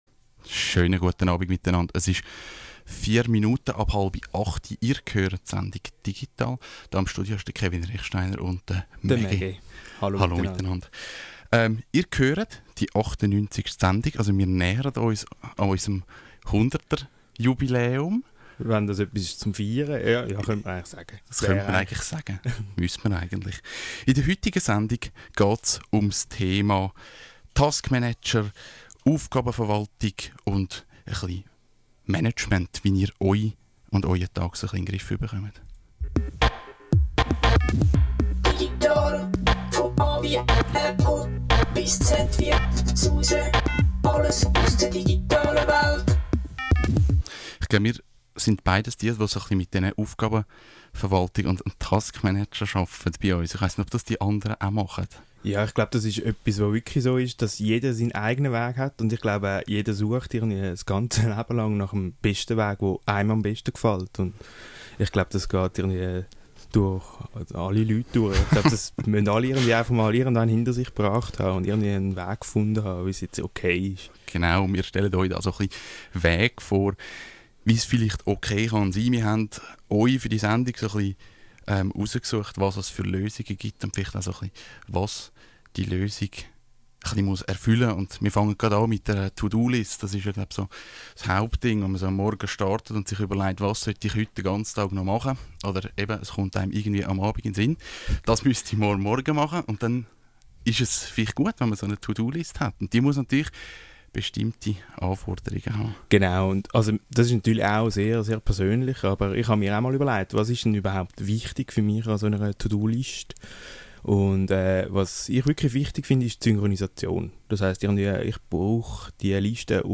Leider gab es bei der Aufnahme der Sendung technische Probleme, weswegen der letzte Teil nicht vollständig zu hören ist.